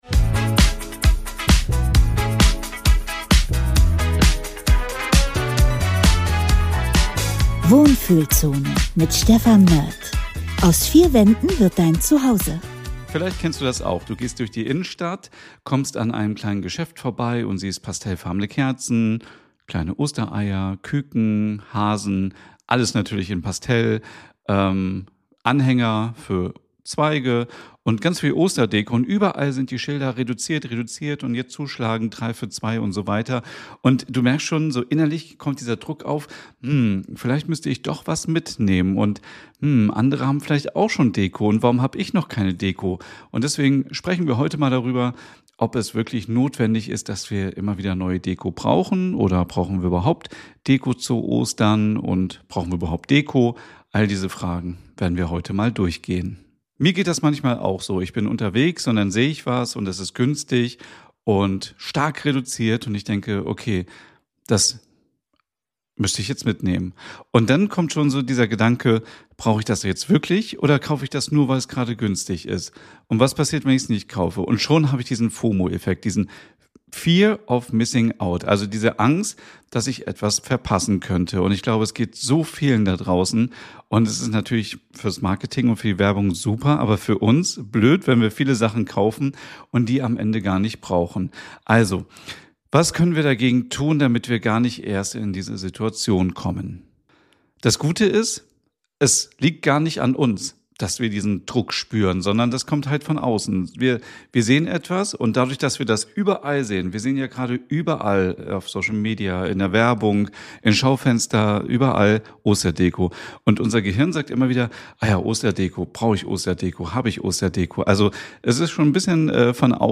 Außerdem sprechen wir darüber, warum dein persönlicher Wohnstil oft über Jahre entsteht – und nicht über eine Saison. Eine ruhige, ehrliche Folge für alle, die ihr Zuhause bewusst gestalten möchten.